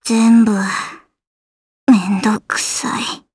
Gremory-Vox_Dead_jp.wav